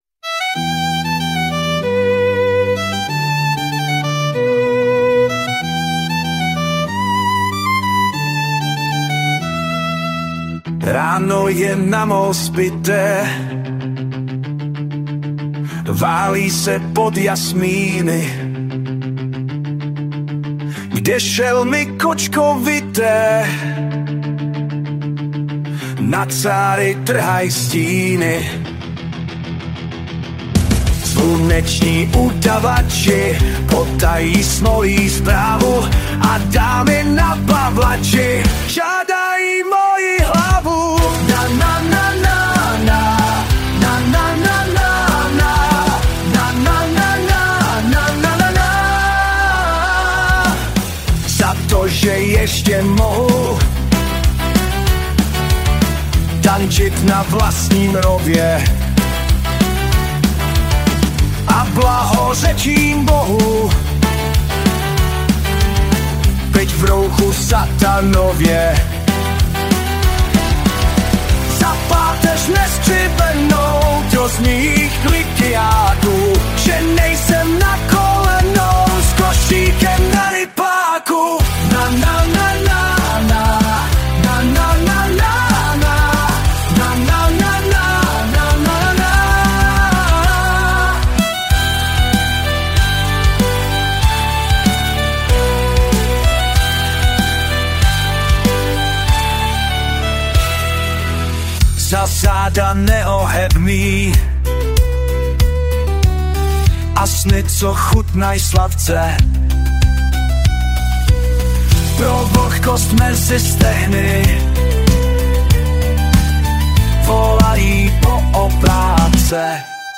zpěv a hudba: AI